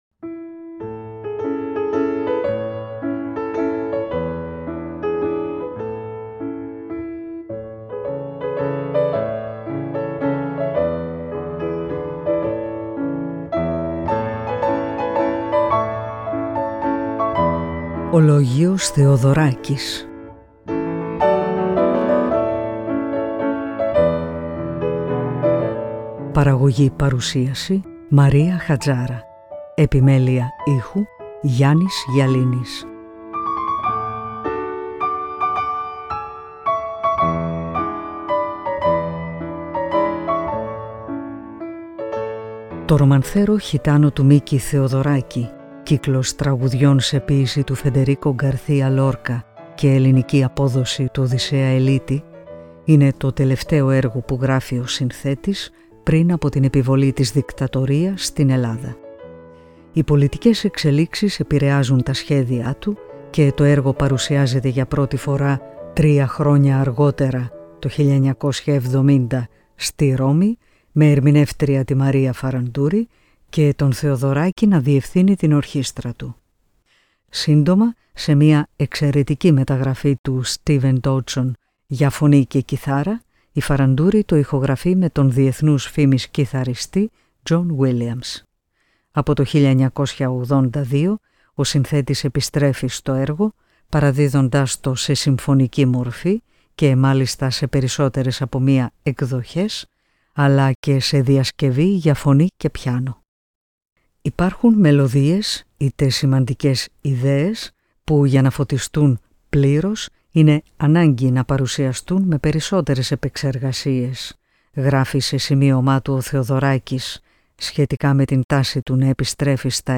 Romancero Gitano – Μεταγραφή για σόλο κιθάρα (2008)